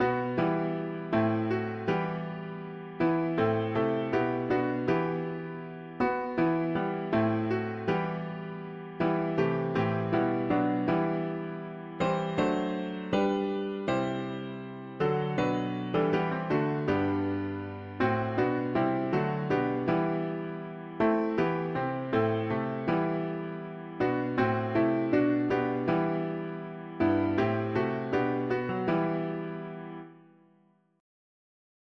May deeper joy for all … english secular 4part
Key: E minor